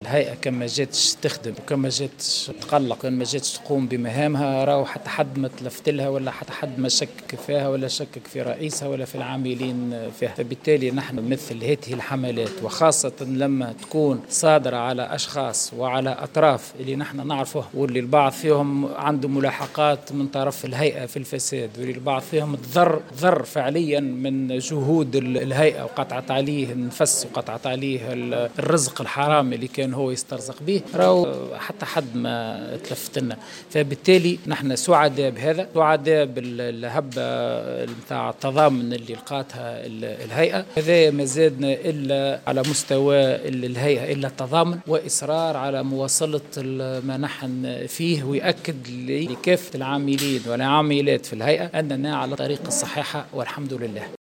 وأوضح في تصريح لمراسل "الجوهرة اف أم" على هامش ندوة اليوم بتونس العاصمة، أن الهيئة أقلقت البعض نظرا لطبيعة تخصصها.